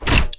wrench_hit_wood2.wav